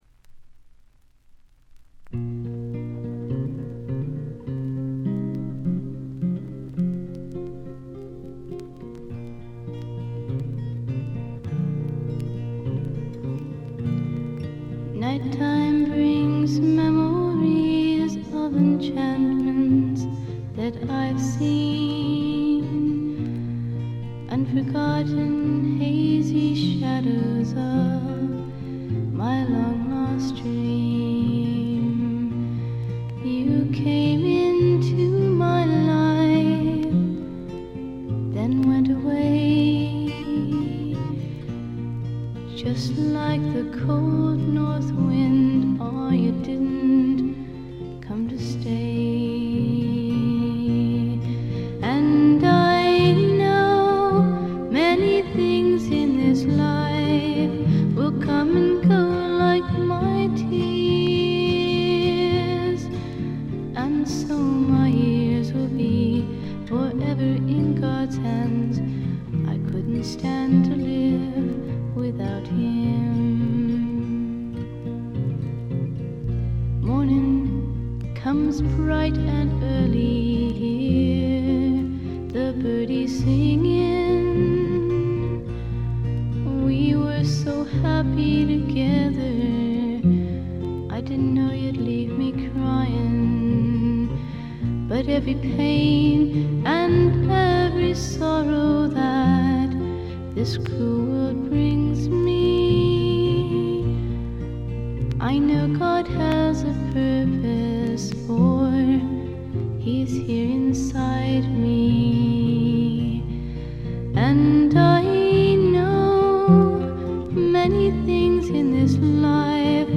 静音部でバックグラウンドノイズ。
演奏はほとんどがギターの弾き語りです。
試聴曲は現品からの取り込み音源です。